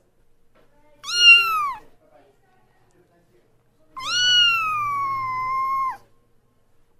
0:00 Group: Tiere ( 134 57 ) Rate this post Download Here!